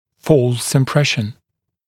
[fɔːls ɪm’preʃn][фо:лс им’прэшн]ошибочное представление, ложное впечатление